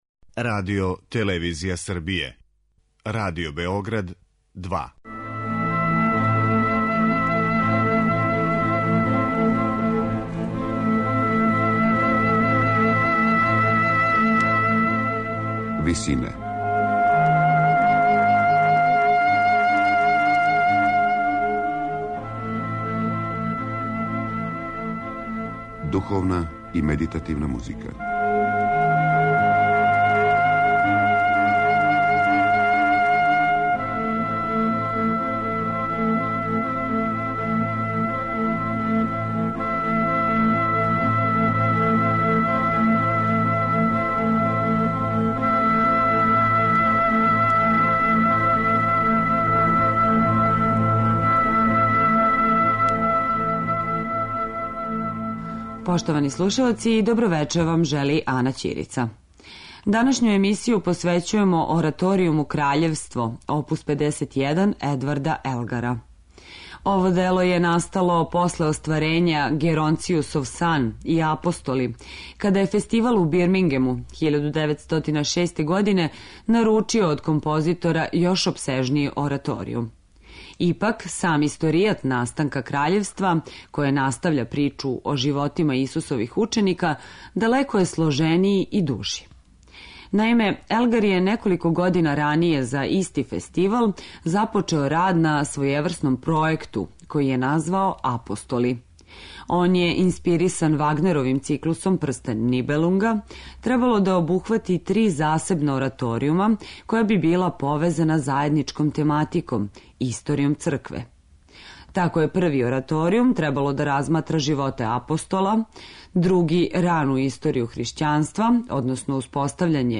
Духовна и медитативна музика